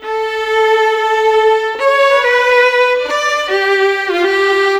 Rock-Pop 10 Violin 01.wav